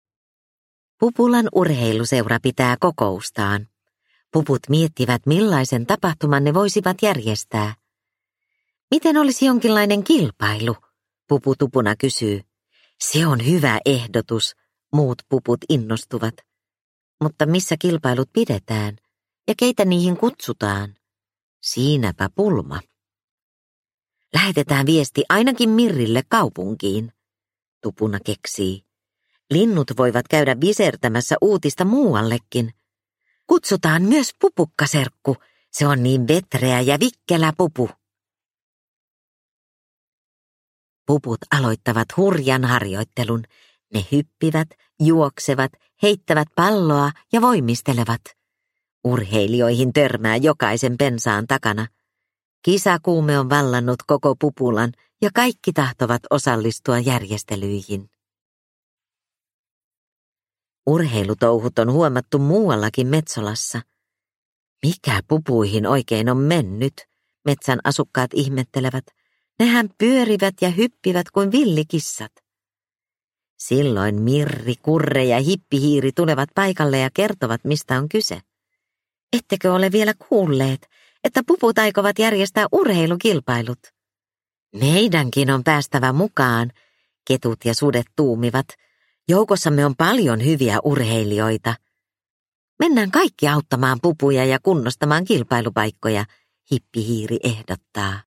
Pupu Tupuna - Metsolan kesäkisat – Ljudbok – Laddas ner